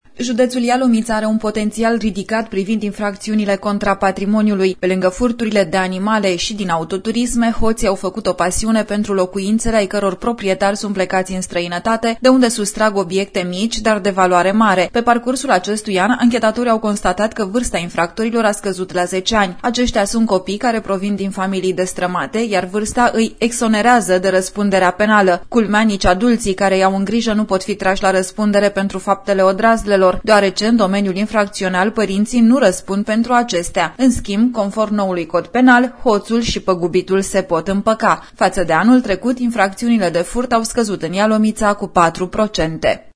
relatare-furturi-si-infractori-minori.mp3